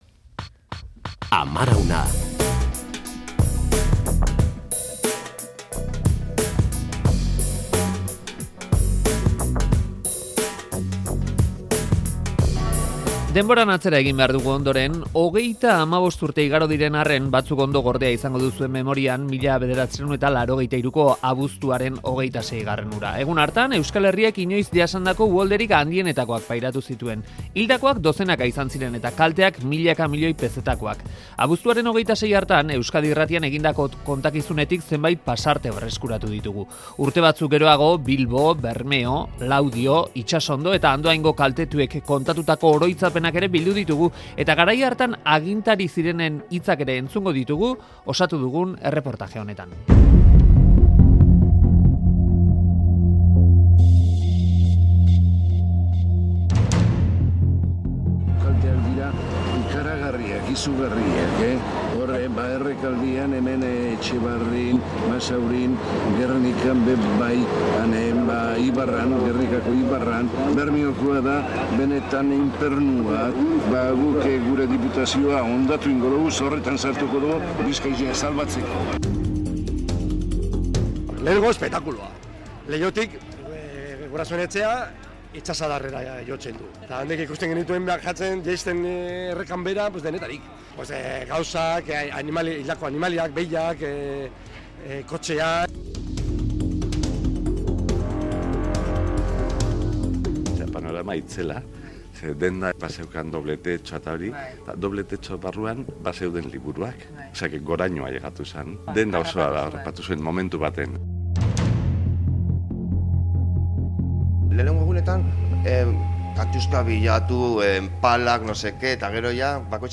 Audioa: Bilbo, Bermeo, Laudio, Itsasondo, Andoain... bete-betean jo zituzten 1983ko abuztuaren 26ko uholdeek. Euskadi Irratian egindako kontakizunetik zenbait pasarte berreskuratu ditugu Amaraunean.